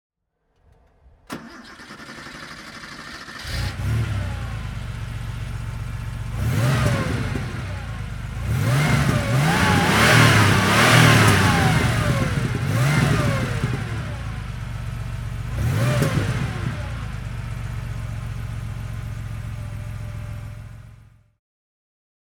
Renault 5 Turbo (1982) - Starten und Leerlauf